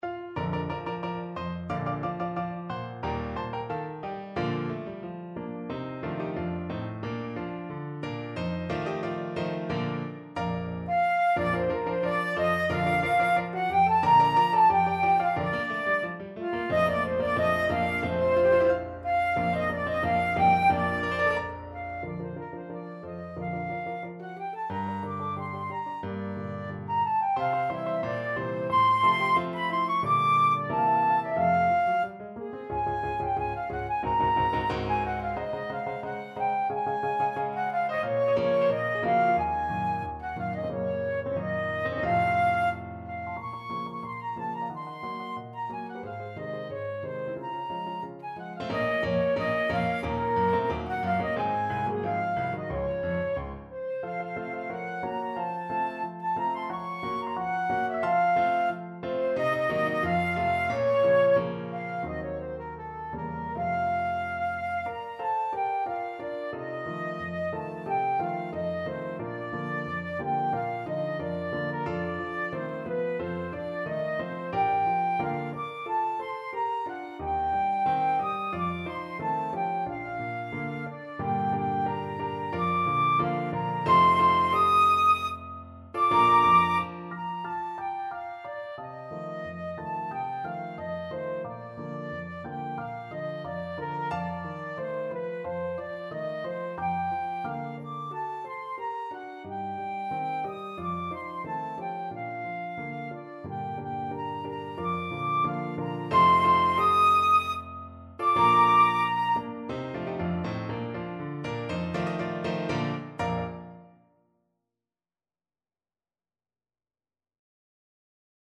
Flute version
Allegretto =90
2/4 (View more 2/4 Music)
Classical (View more Classical Flute Music)